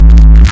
Desecrated bass hit 07.wav